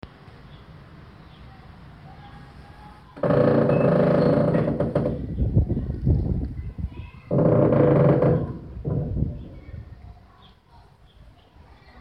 Tainan old city gate sound